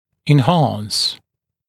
[ɪn’hɑːns] [en-][ин’ха:нс] [эн-]увеличивать, усиливать, улучшать